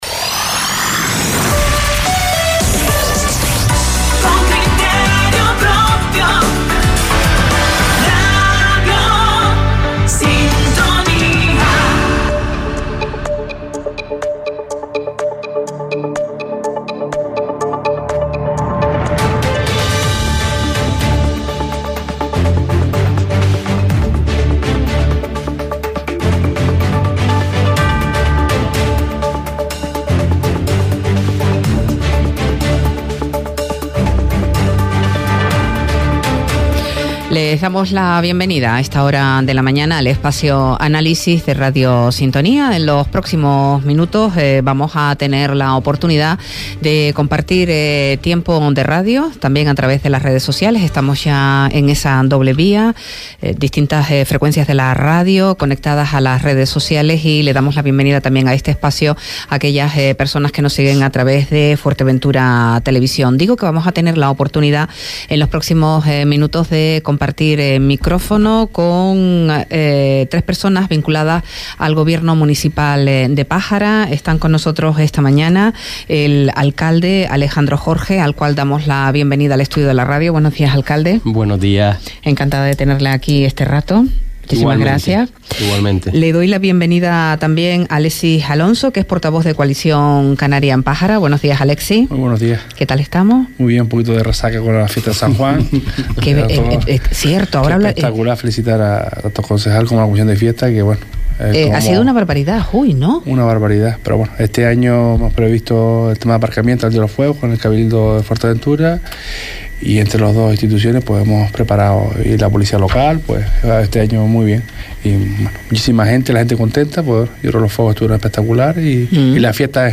Análisis del primer año de legislatura en Pájara para el alcalde, Alejandro Jorge, y los portavoces de CC y PSOE, Alexis Alonso y Raimundo Da Costa respectivamente.